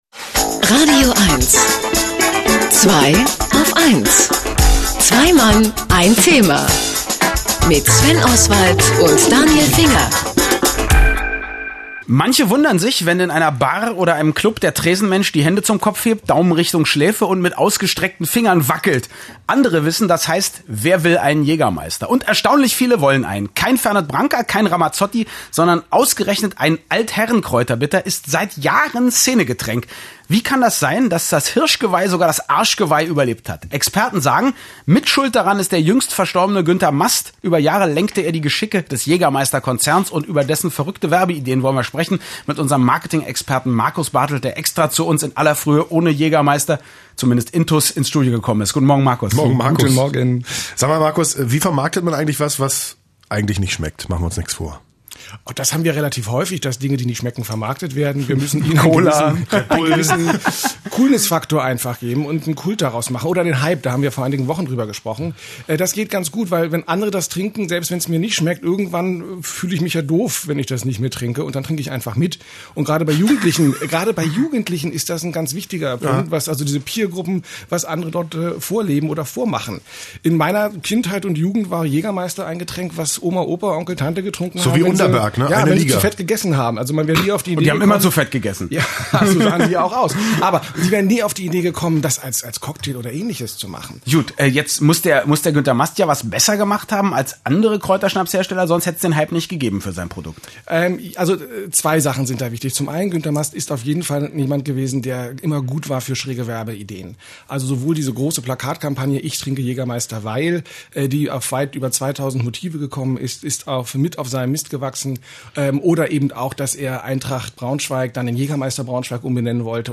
Und wieder begeben wir uns auf die Erinnerungsstraße und entdecken längst vergessene Perlen meiner radioeins-Interviews wieder….